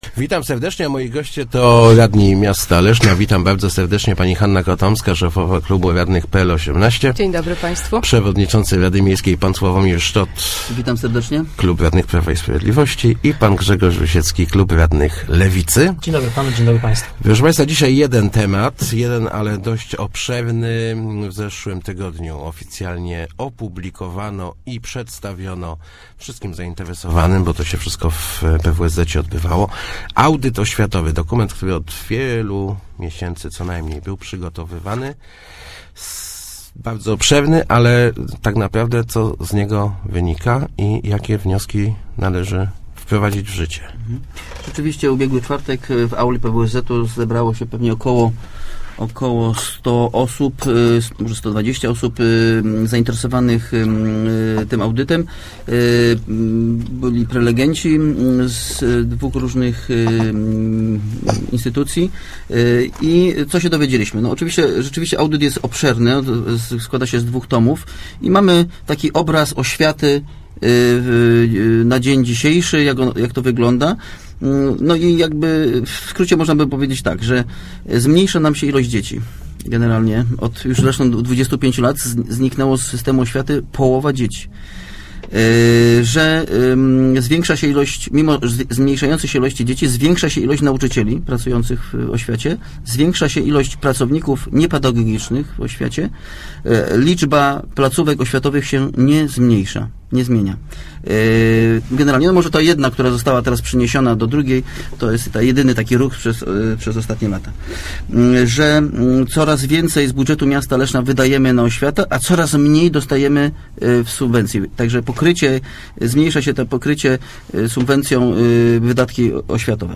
O�wiata w Lesznie jest najdro�sza z porównywalnych miast – mówi� w Kwadransie Samorz�dowym S�awomir Szczot (PiS), przewodnicz�cy RML, podsumowuj�c zaprezentowany w�a�nie audyt konkretnych rozwi�za�.